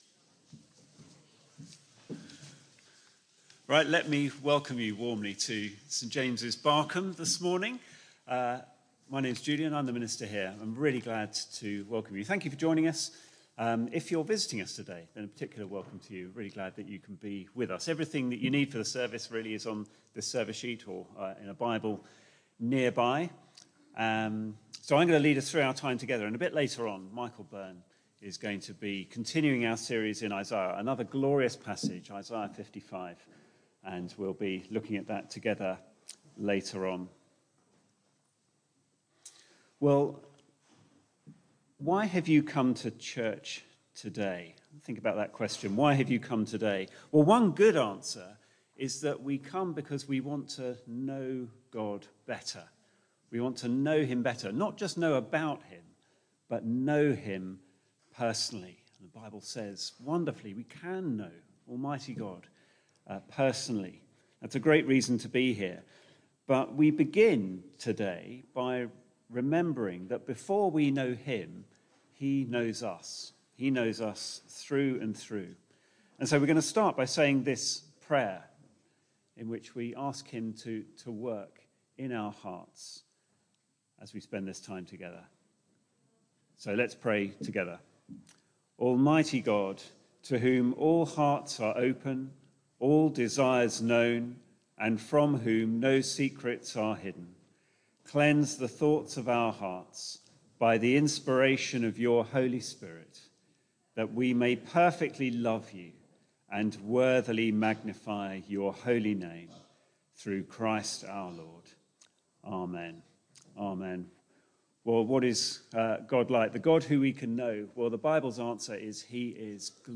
Media for Barkham Morning Service on Sun 26th Mar 2023 10:00
Full service recording Reading and sermon recording